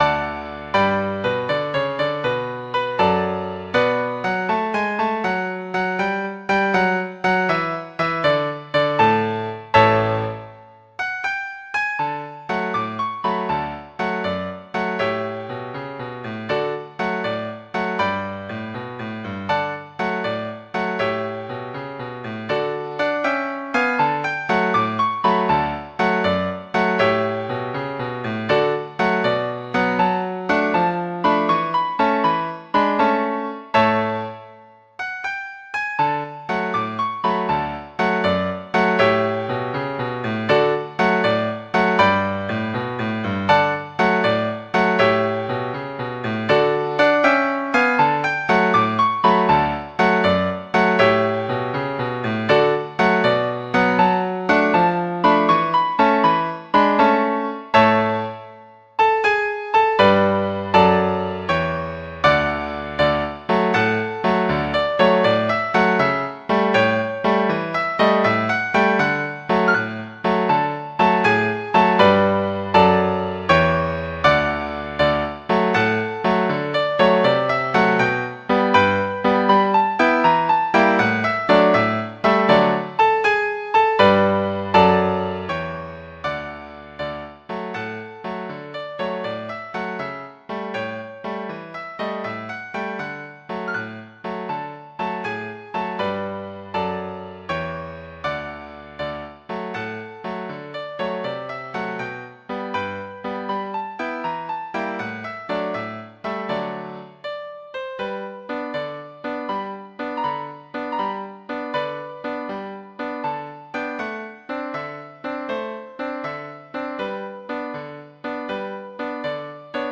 Classical Sousa, John Philip The Invincible Eagle Piano version
Piano Classical Piano Classical Piano Free Sheet Music The Invincible Eagle